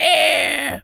pgs/Assets/Audio/Animal_Impersonations/bird_large_squawk_07.wav at master
bird_large_squawk_07.wav